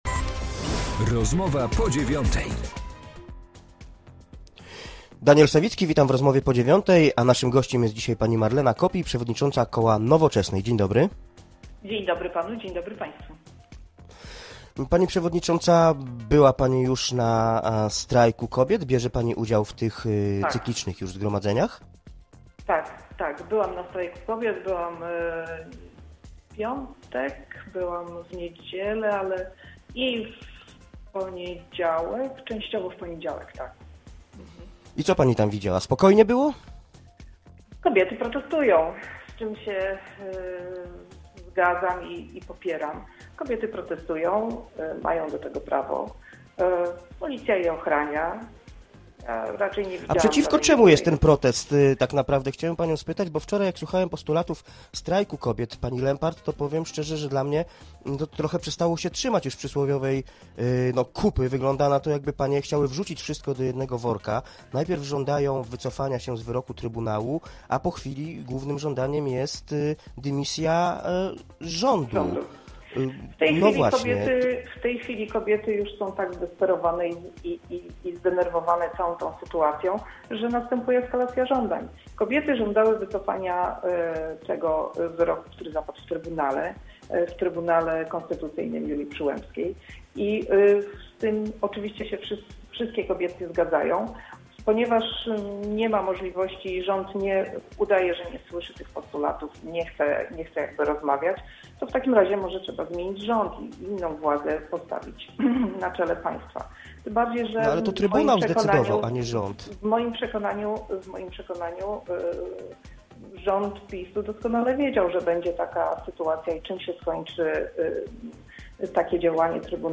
Z przedstawicielką Nowoczesnej rozmawia